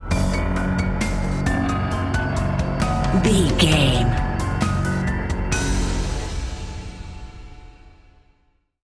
Aeolian/Minor
eerie
haunting
drum machine
piano
synthesiser